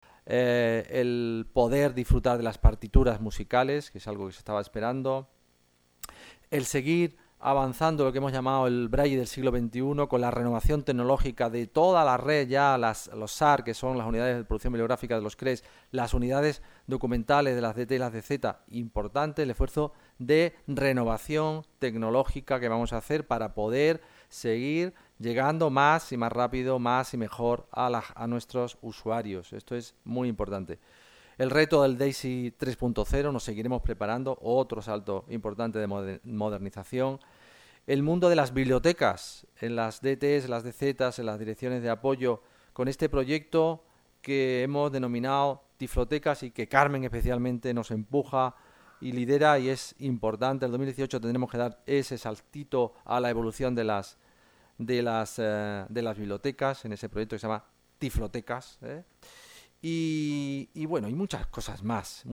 avance de los proyectos en marcha este año, formato MP3 audio(0,95 MB) para este área, en un acto celebrado el día 24.